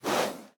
nosebreath3.ogg